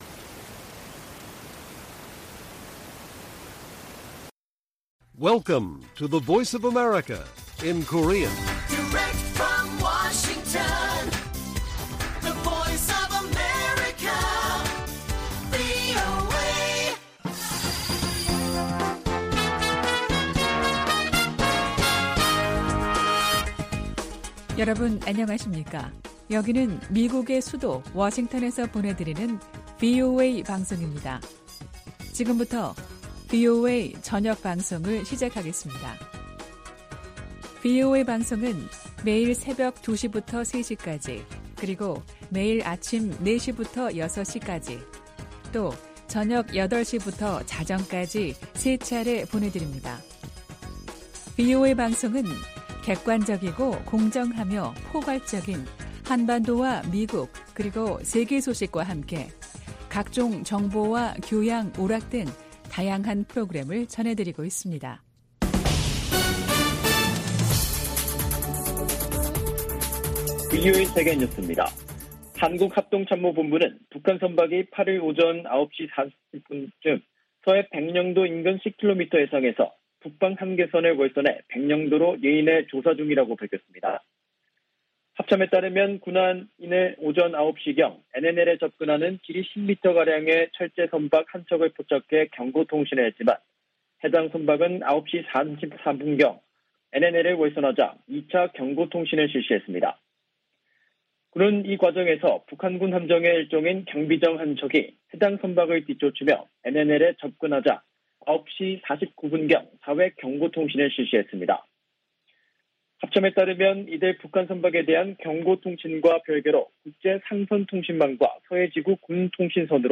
VOA 한국어 간판 뉴스 프로그램 '뉴스 투데이', 2022년 3월 8일 1부 방송입니다. 북한의 탄도미사일 발사에 대한 유엔 안보리의 공식 대응이 다시 무산됐습니다. 북한 미사일 도발에 국제사회 비난이 커지는 가운데 북-중-러 삼각 연대가 강화되는 양상을 보이고 있습니다. 북한이 영변과 강선 등지에서 핵 활동을 지속하는 징후가 있다고 국제원자력기구(IAEA) 사무총장이 밝혔습니다.